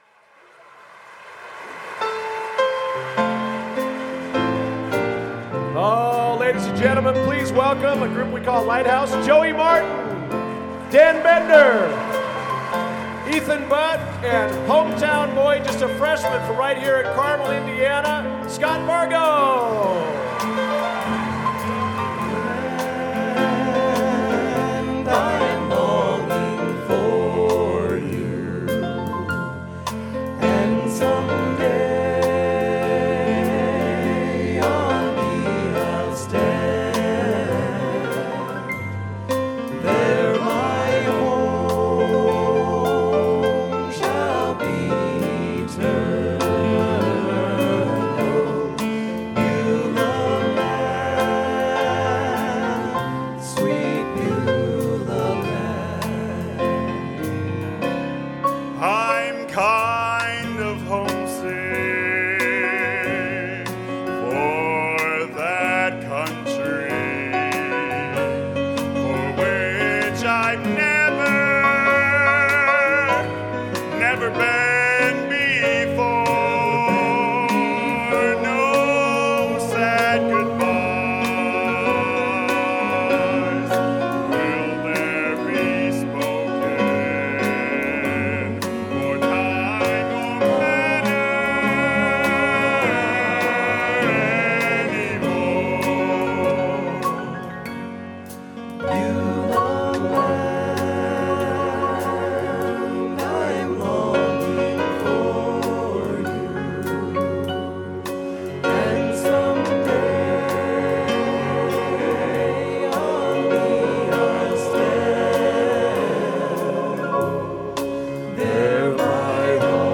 Location: Carmel High School, Carmel, Indiana
Genre: Gospel | Type: Specialty